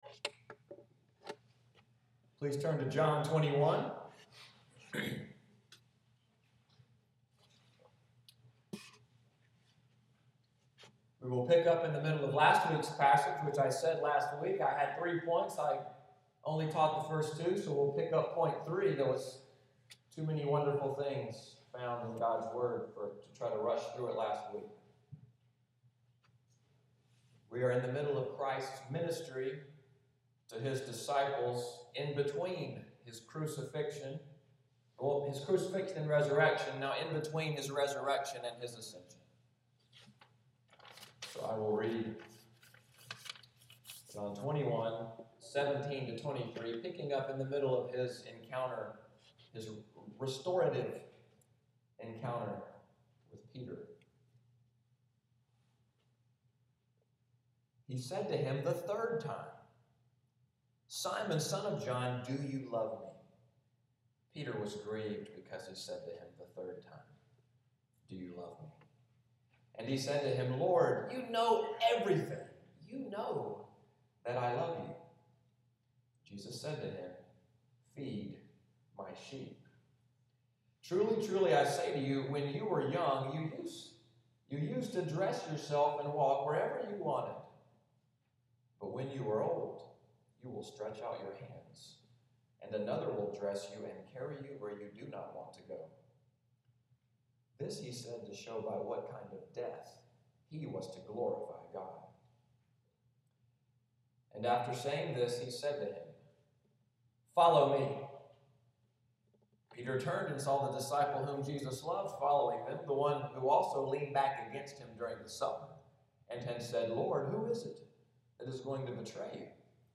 Audio of the sermon, “He Knows and He Cares,” May 18, 2014